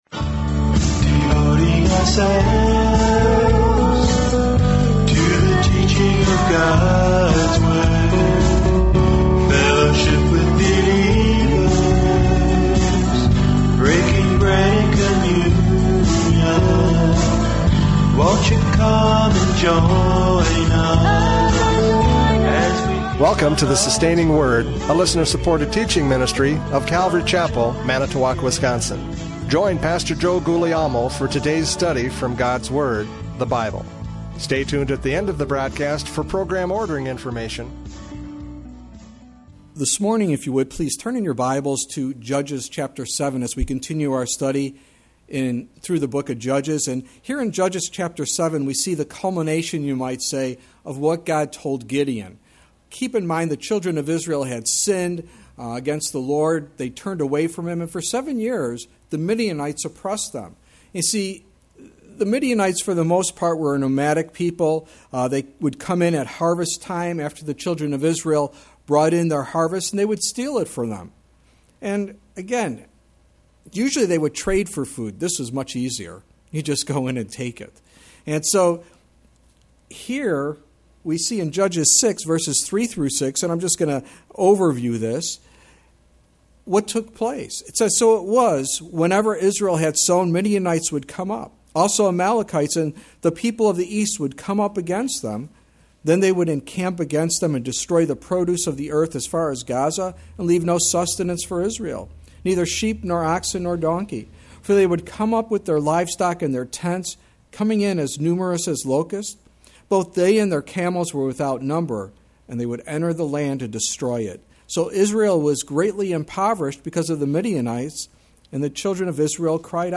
Judges 7:16-25 Service Type: Radio Programs « Judges 7:8-15 The Encouragement of God!